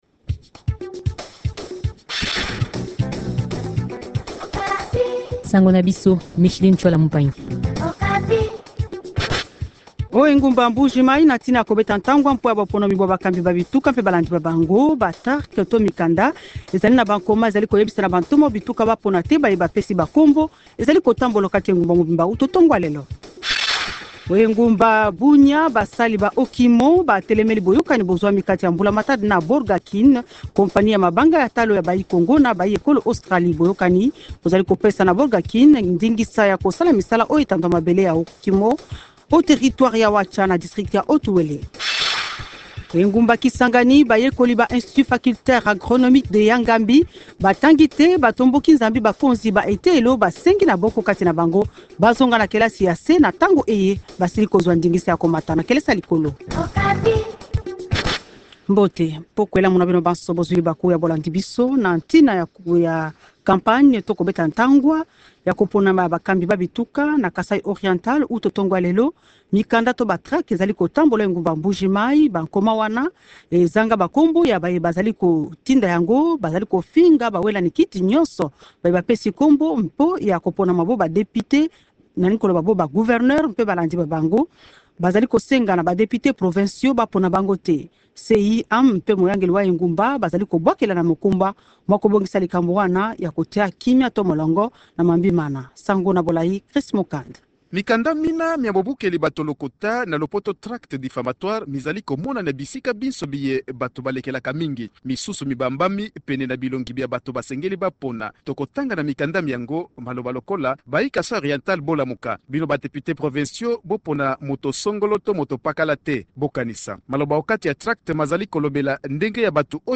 Journal Lingala